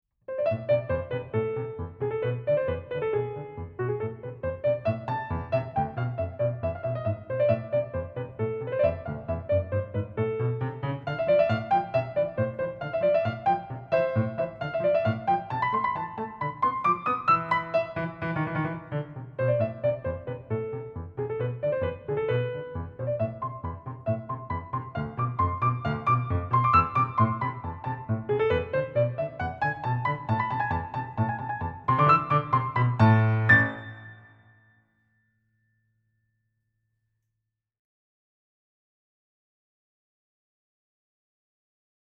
Audio demonstrations